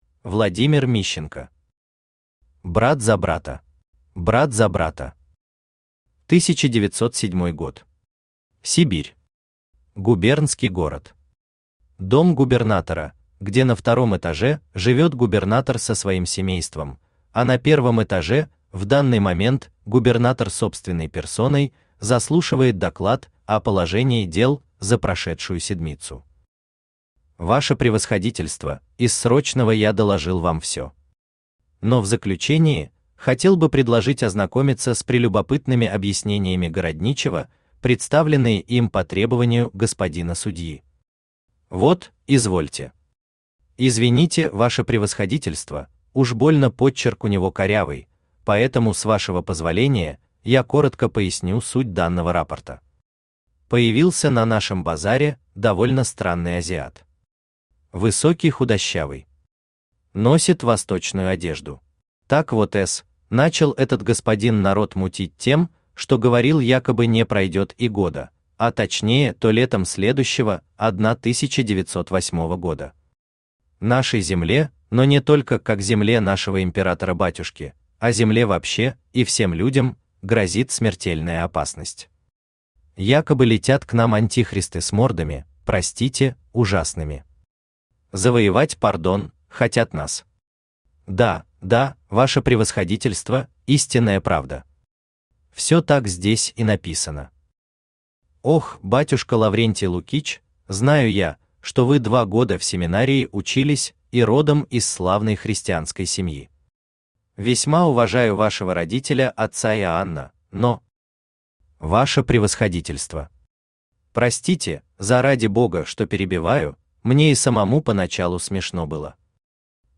Аудиокнига Брат за брата | Библиотека аудиокниг
Aудиокнига Брат за брата Автор Владимир Мищенко Читает аудиокнигу Авточтец ЛитРес.